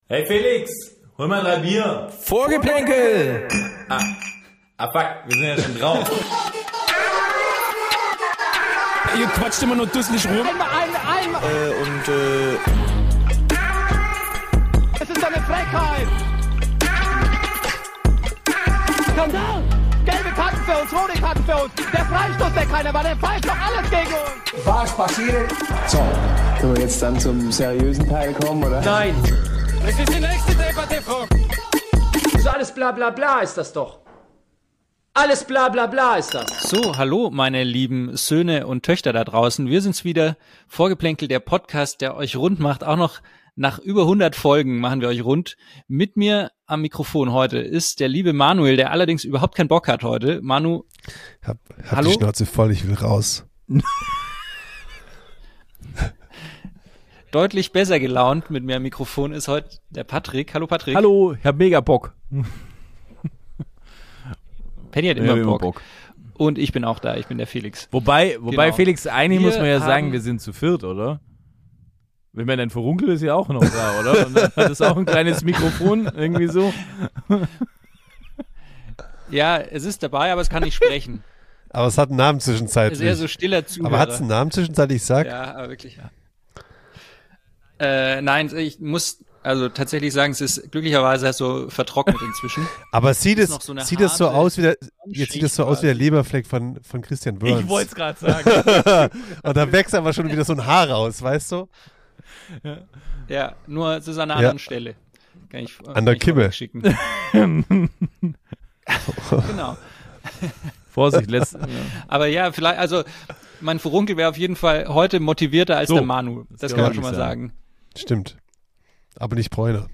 Vorgeplänkel klingt nach der Kneipe am Eck.
Nicht immer was für Feingeister, eher ungeschliffen, direkt, gelegentlich unlogisch und mit Halbwissen garniert.